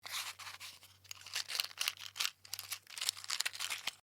Match Sticks Fidget
SFX
yt_UpHgI13e3cw_match_sticks_fidget.mp3